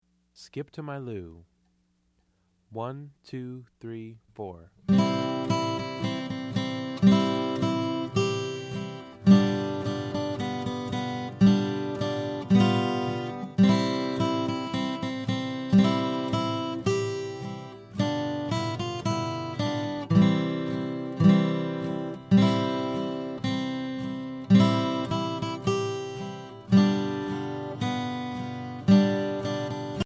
Arranger: Folk Songs
Voicing: Guitar Method